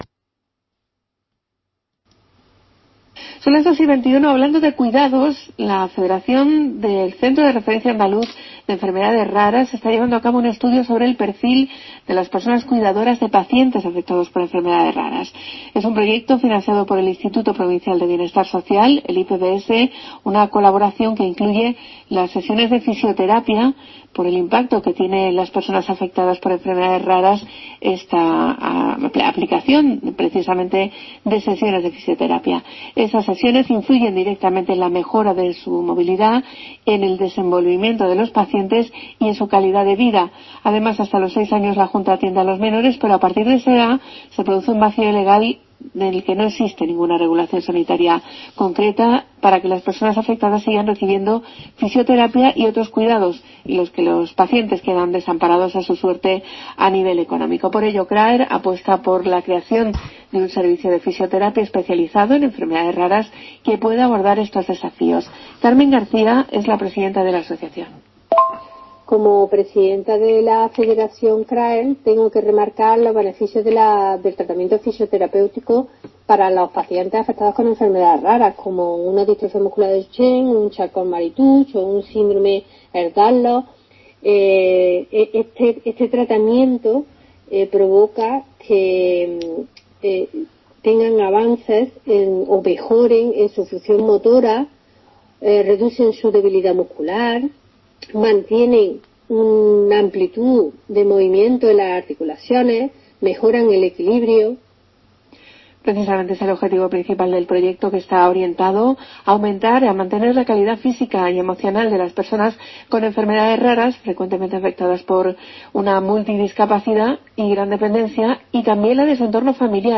ENTREVISTA RADIO SER- HORA 14